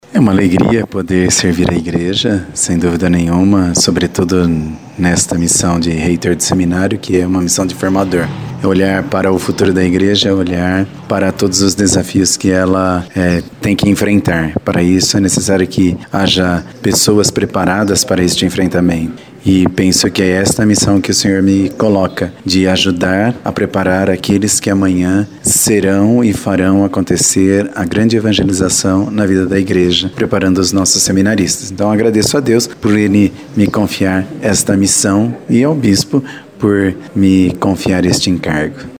falou com nossa reportagem como acolheu o convite: